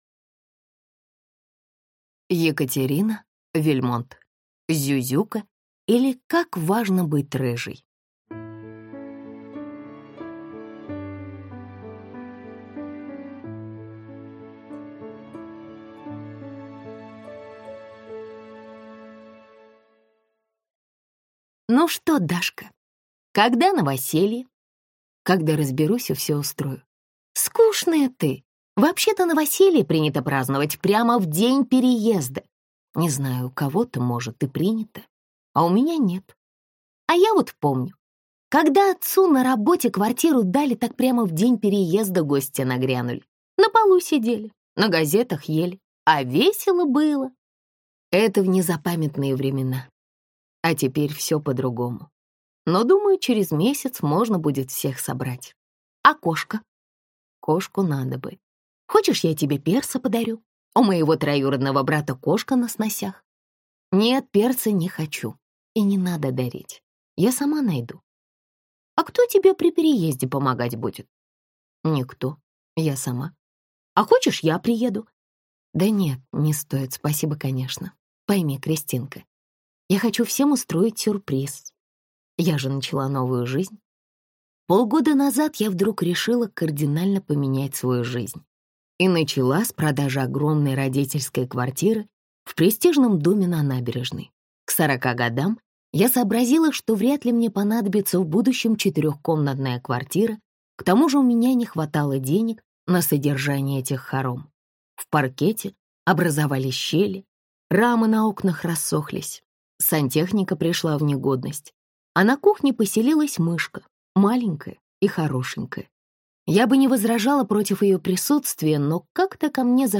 Аудиокнига Зюзюка, или Как важно быть рыжей | Библиотека аудиокниг